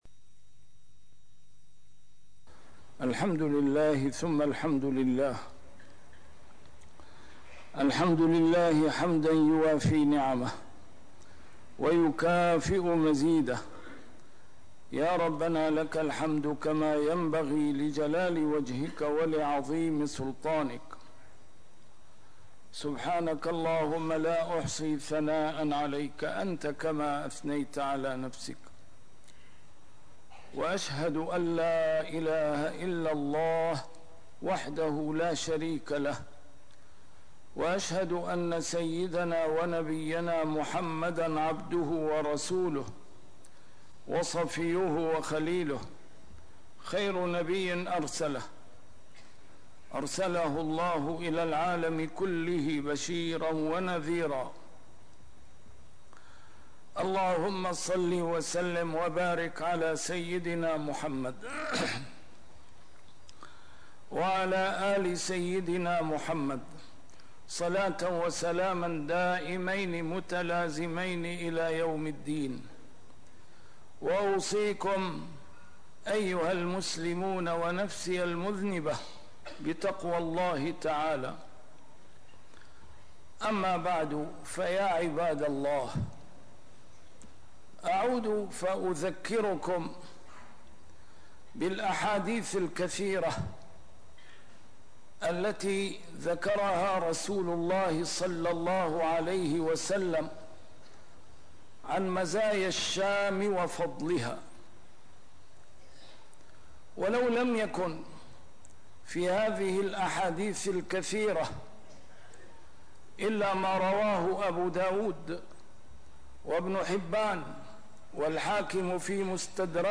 نسيم الشام › A MARTYR SCHOLAR: IMAM MUHAMMAD SAEED RAMADAN AL-BOUTI - الخطب - آن الأوان أن نستعلن بهويتنا الإسلامية؟!